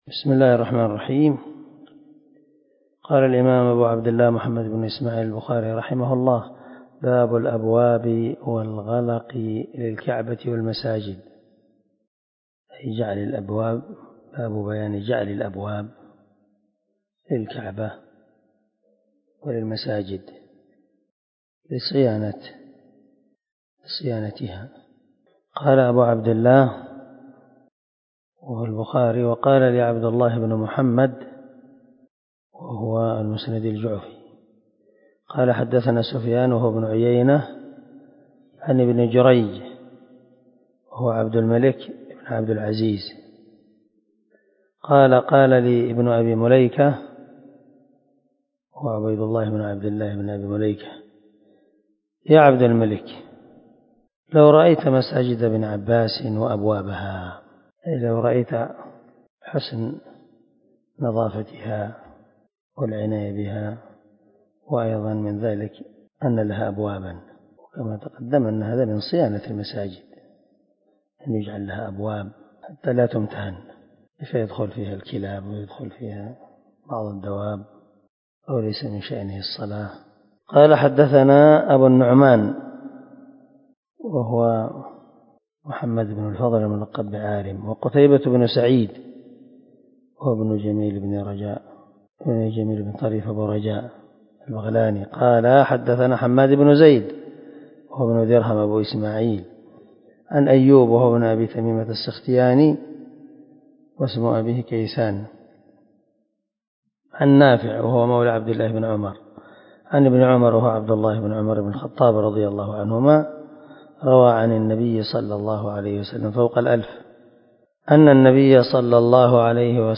347الدرس 80 من شرح كتاب الصلاة حديث رقم ( 468 ) من صحيح البخاري